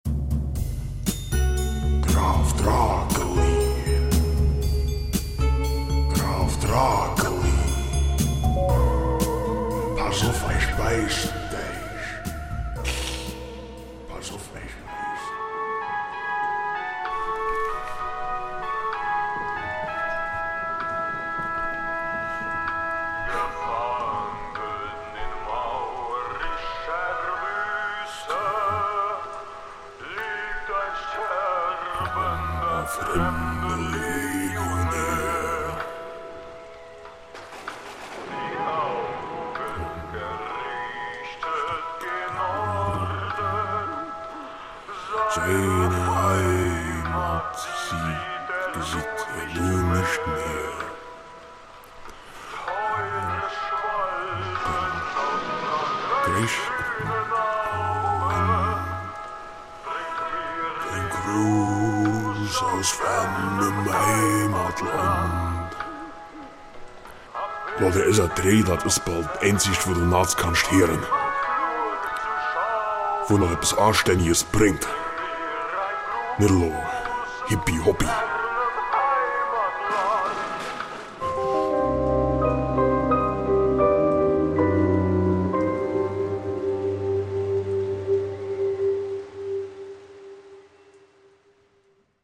Graf Drakeli ist ein ganz normaler Vampir und wohnt in einem Schloss. Von dort grantelt er auf hochwälder Platt und mit schrägem Humor über die Widrigkeiten des Alltags.
Comedy